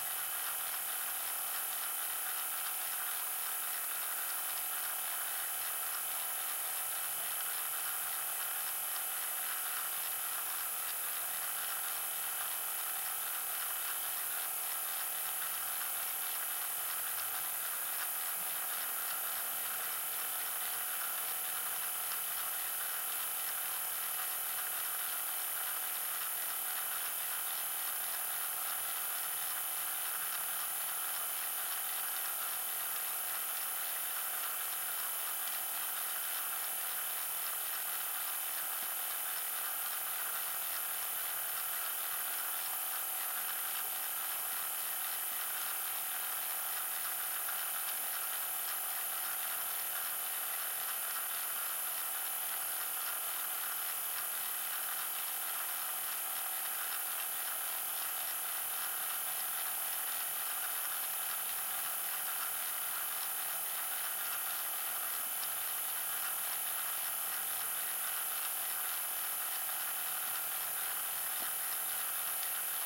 描述：The sound of a buzzing light edited
标签： Buzz Noise Electronic Mechanical
声道立体声